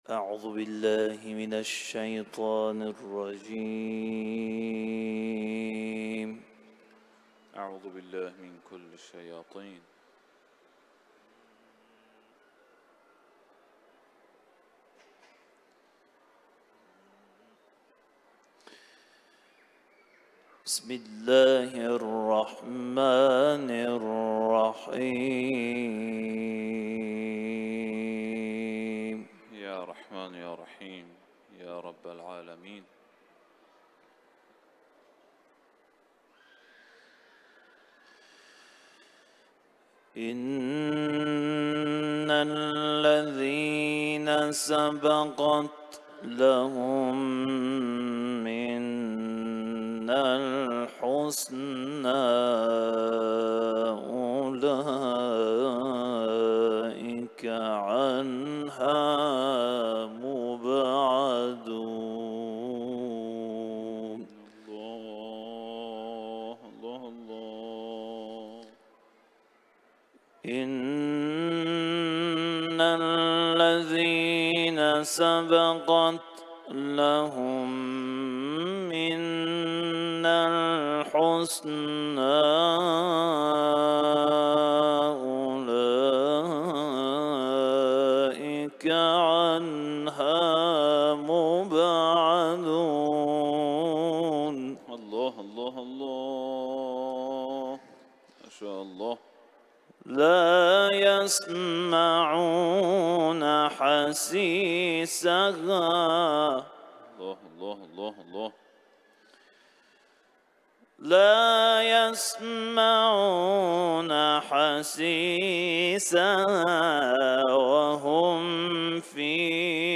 Etiketler: İranlı kâri ، Kuran tilaveti ، Enbiya suresi